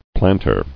[plant·er]